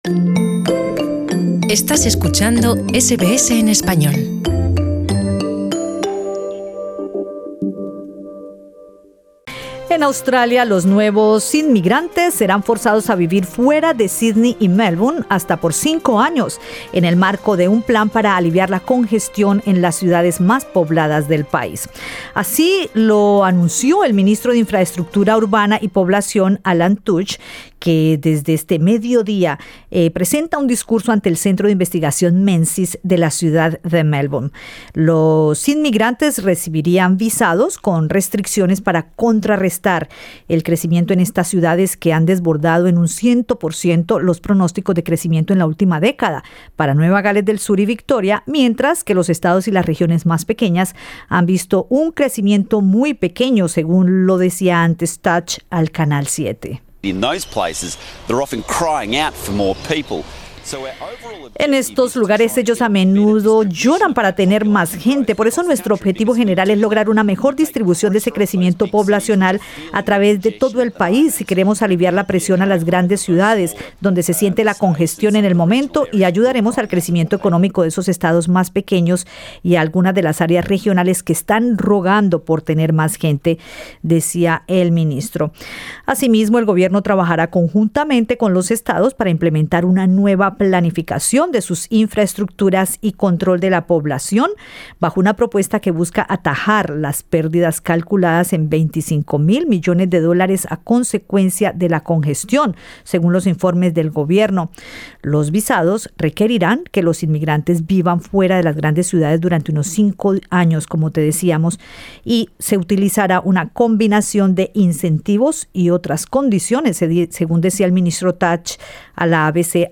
Escucha el podcast con la conversación con el consultor de Inmigración